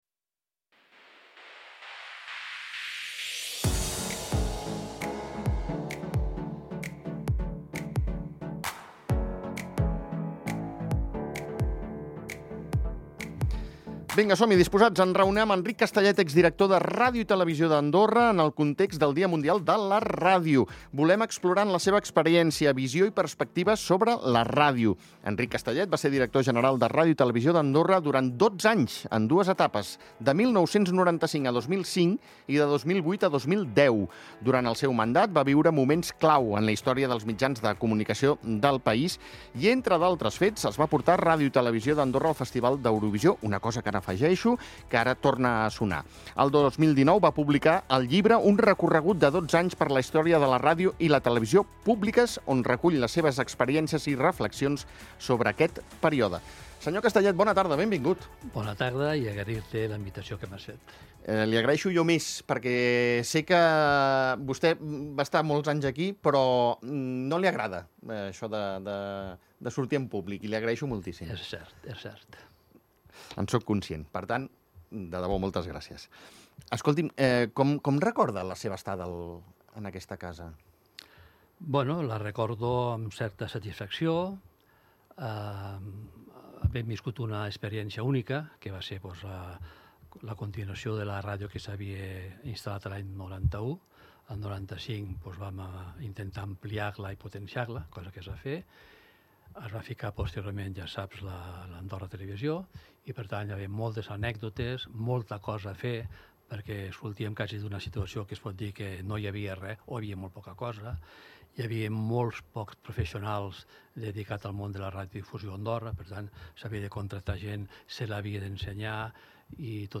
ha sortit al carrer i us ha preguntat una sèrie de qüestions al voltant de la ràdio